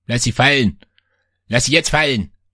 描述：德国军方指挥与男声说话。
标签： 说话 语音 语音 军事 突击队 指挥 声乐 德语 德语
声道立体声